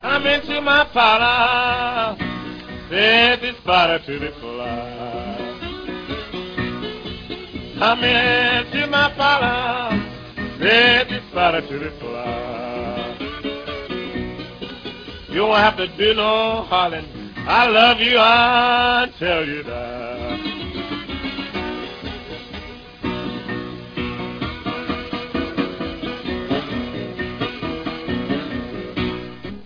Оба брата предпочитали играть на 12-струнной гитаре.